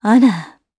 Isolet-Vox_Happy4_jp.wav